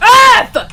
Worms speechbanks
Dragonpunch.wav